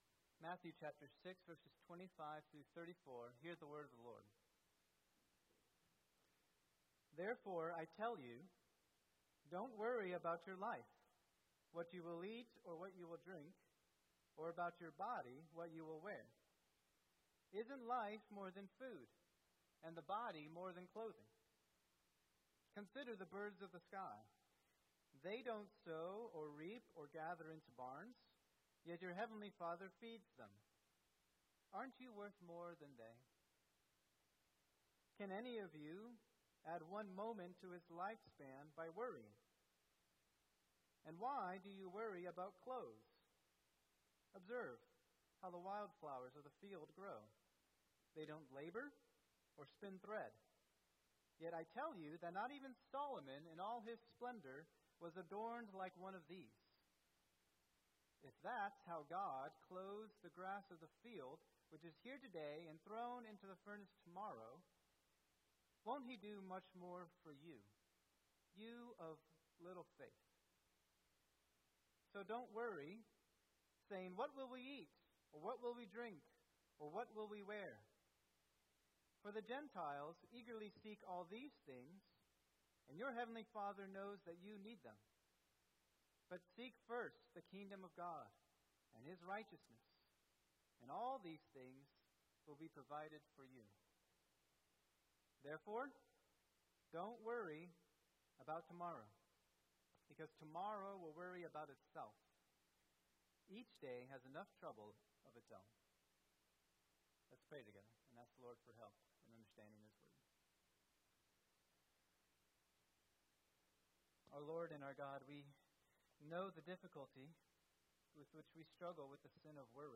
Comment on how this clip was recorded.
2024 at First Baptist Church in Delphi, Indiana.